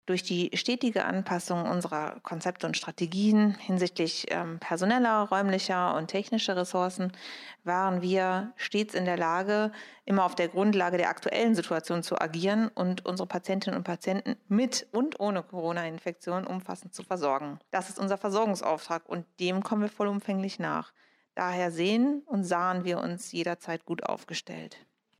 o-ton-anpassung.mp3